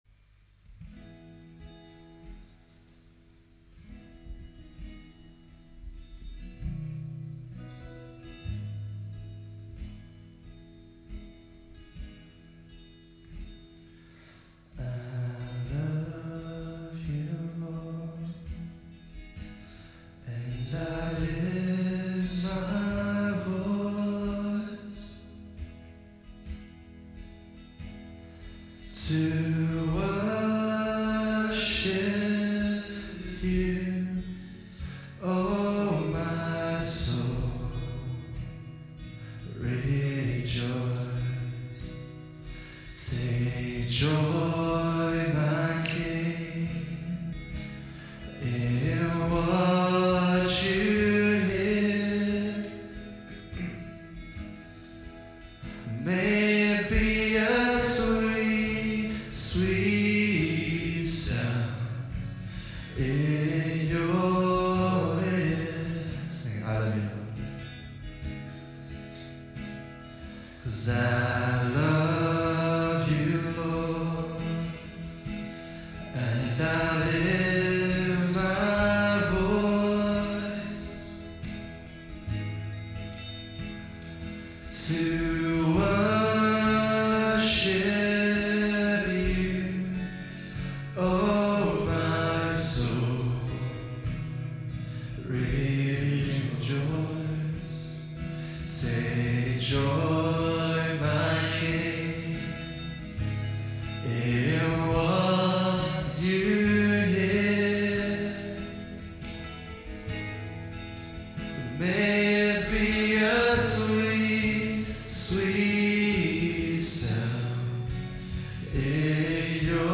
Worship November 15, 2015 – Birmingham Chinese Evangelical Church